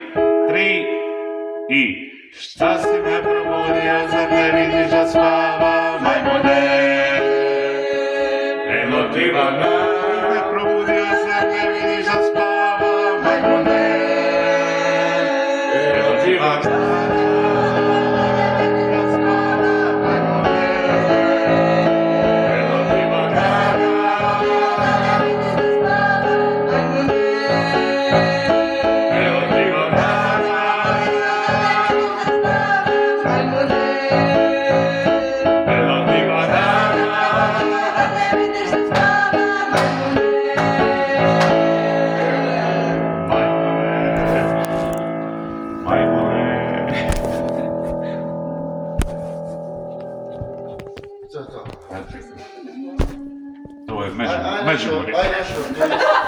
Arrangment for pop Rock bend